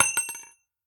nut_impact_09.ogg